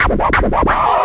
Scratch 1.wav